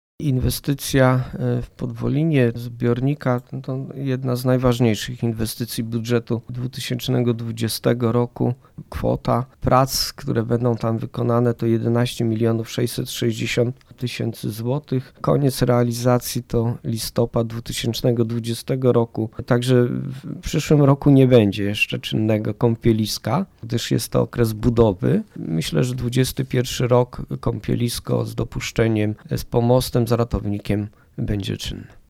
Mówi burmistrz Niska Waldemar Ślusarczyk: